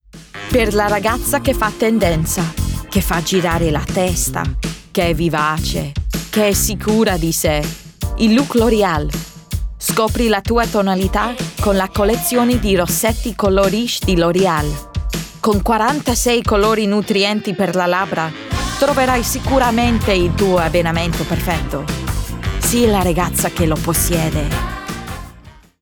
RP ('Received Pronunciation')
Commercial, Bright, Light, Warm